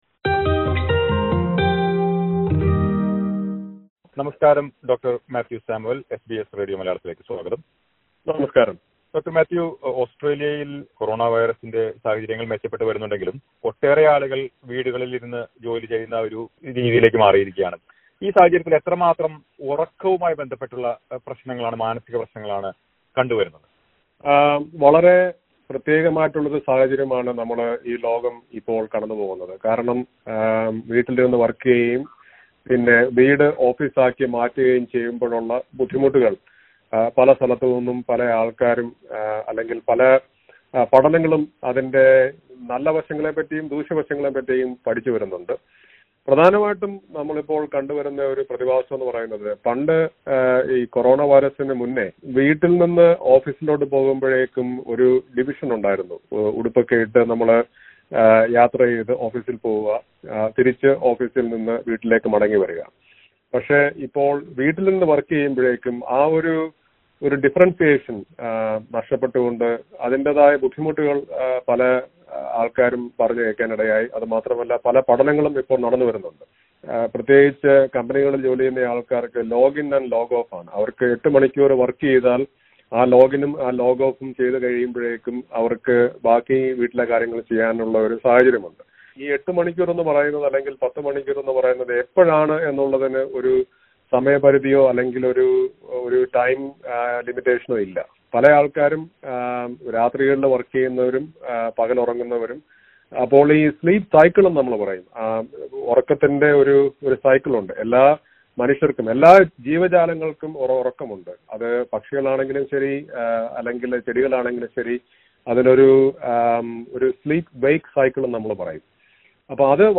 Disclaimer: The information provided in this interview is general in nature.